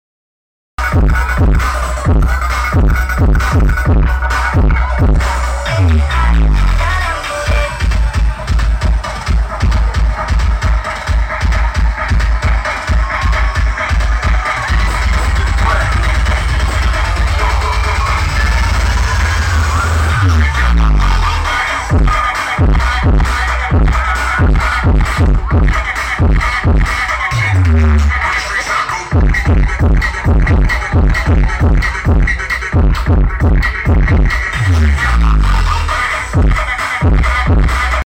pesta kampung batauga lampanairi di sound effects free download